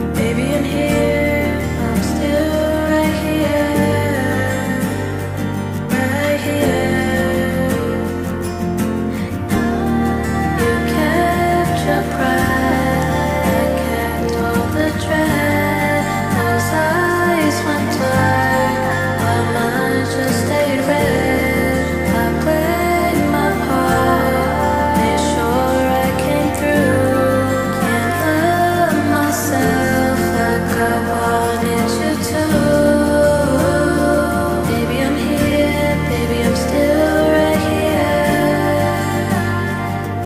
there’s a specific vibe and atmosphere i want to capture while keeping the raw emotion of the original. my sound is inspired by the alternative rock/indie/shoegaze genres.
here’s a rough mix of the song so far.